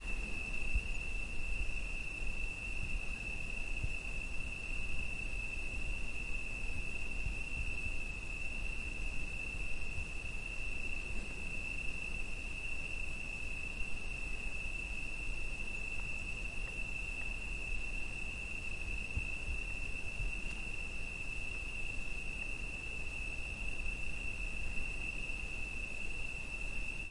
蝉单
描述：希腊夏季最明显的声音。在其领域中的单一蝉。
标签： 氛围 蟋蟀 现场 昆虫 性质 现场记录
声道立体声